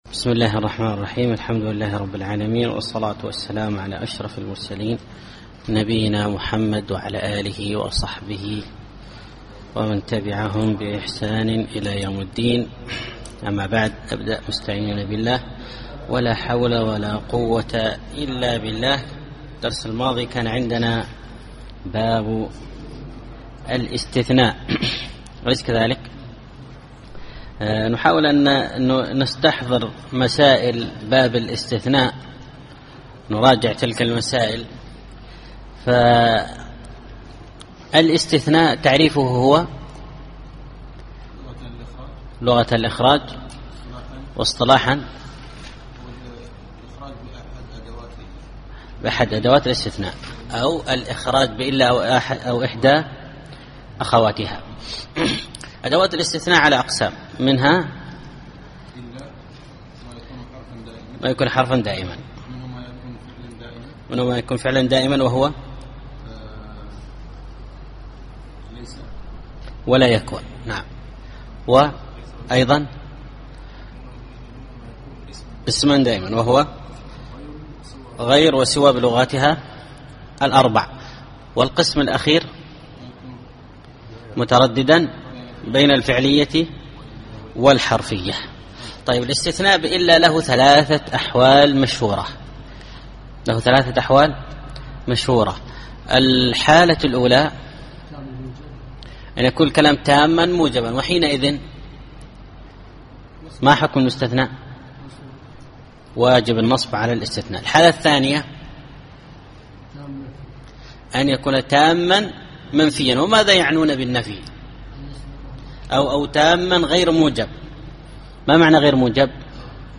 الدرس السابع عشر الأبيات 183-188